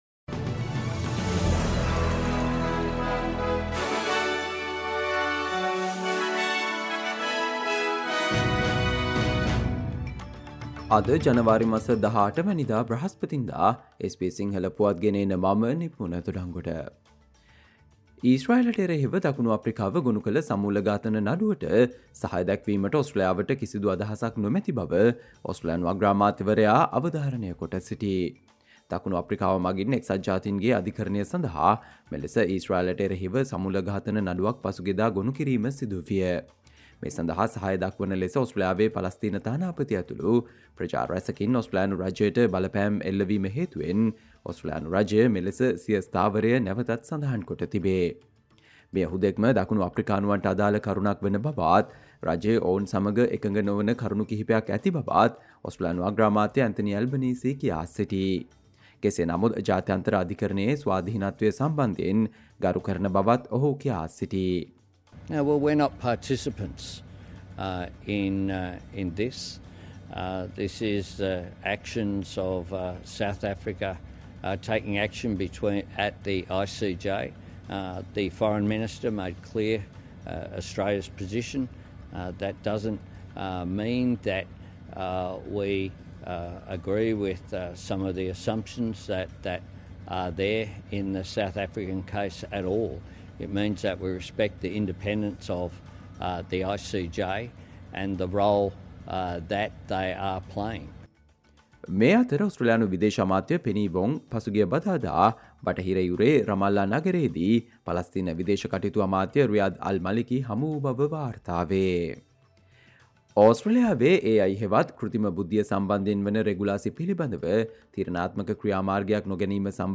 Australia news in Sinhala, foreign and sports news in brief - listen, Thursday 18 January 2024 SBS Sinhala Radio News Flash